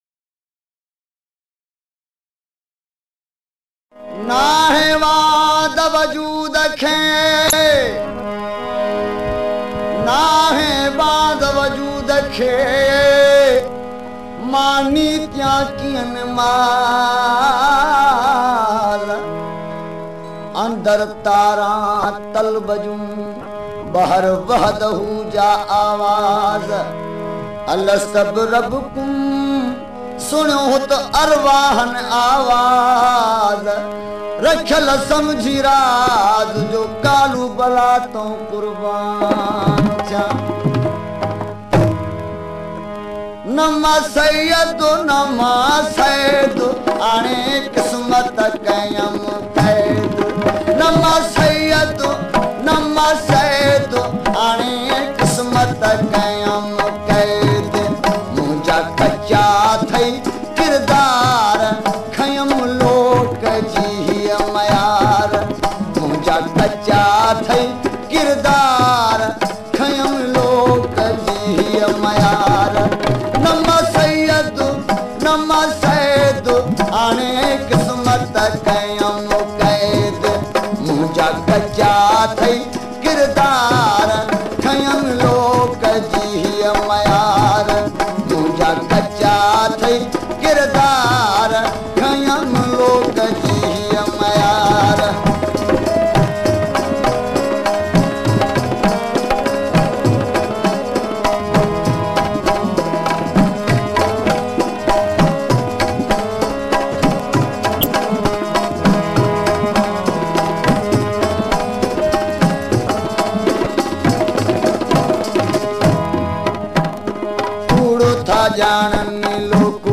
Sindhi ﺴﻨﺪﻫﻰ Sufi Music [Pakistan]